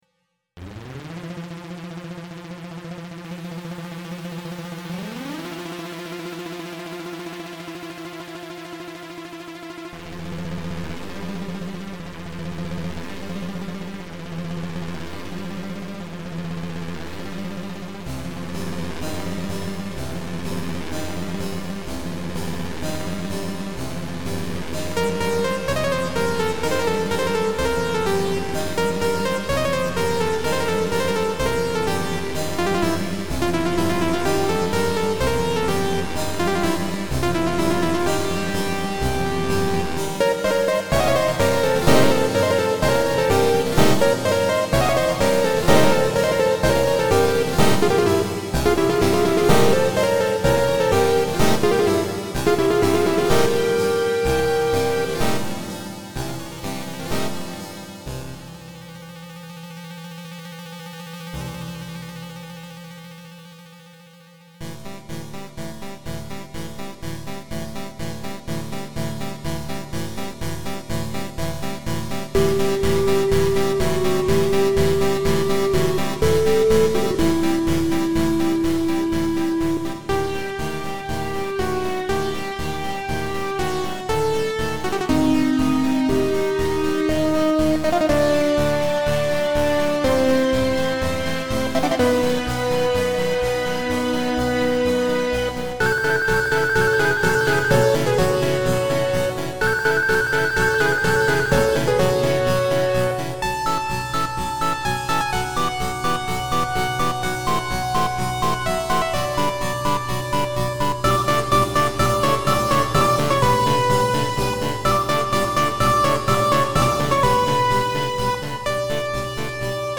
La Danza Ritual del Fuego by Manuel de Falla but make it JRPG Fire Dungeon with a lava-hopping puzzle that will melt your CPU trying to keep up with all these trills.